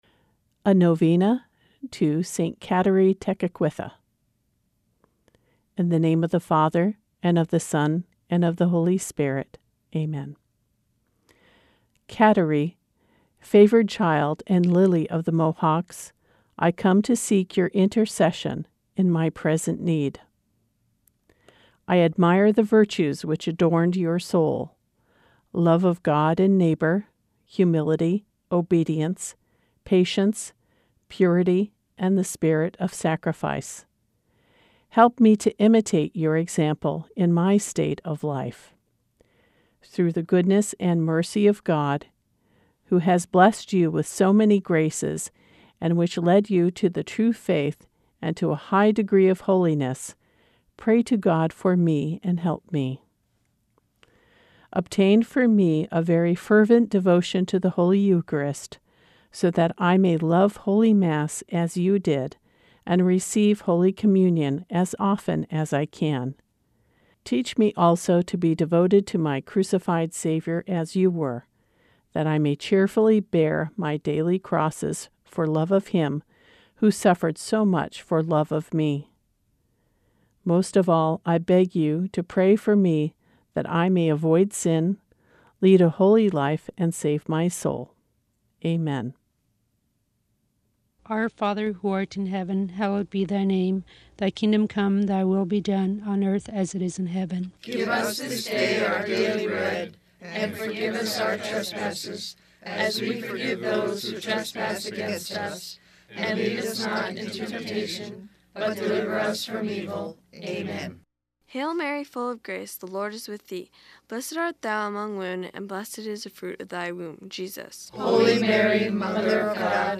Both prayer recordings were produced at KNOM by members of the Catholic community in Nome and surrounding villages.
In 2016, we produced a simple but special prayer devotion to St. Kateri, to be broadcast for a novena (nine-day period) starting on our 45th birthday, July 14, 2016.
KNOM-Radio-St-Kateri-Novena.mp3